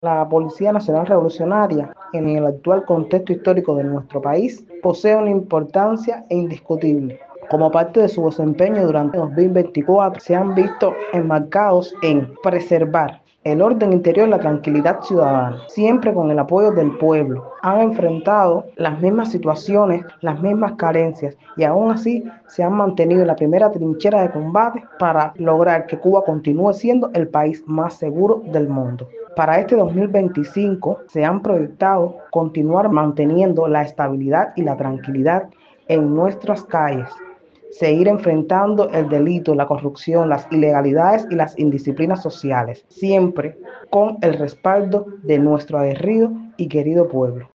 Al respecto, conversamos con Marina Yadelsy Morejón Aldama, diputada a la Asamblea Nacional del Poder Popular y oficial de Coordinación y Apoyo del jefe del Ministerio del Interior de este municipio, quien en declaraciones a nuestra emisora abordó el relevante papel que ocupa la Policía Nacional Revolucionaria en el escenario sociopolítico en que se desenvuelve nuestro país.